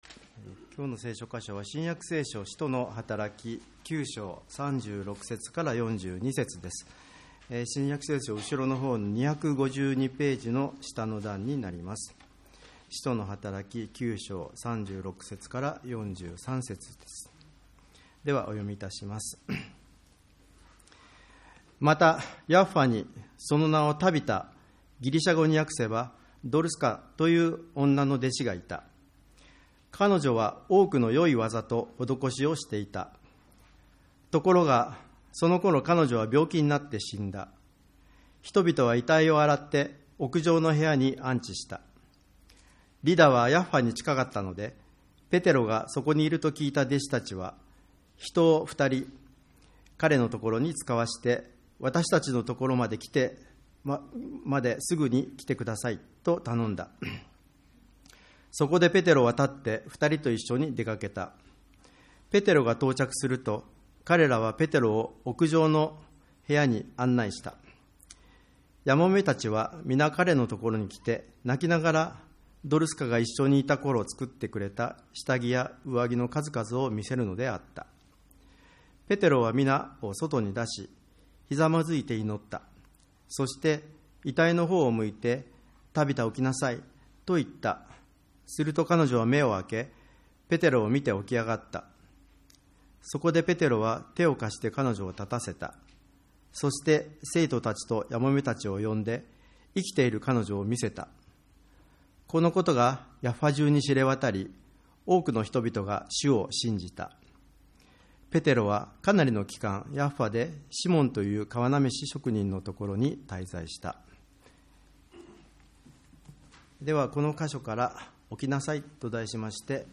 2024.1.7 主日礼拝
礼拝メッセージ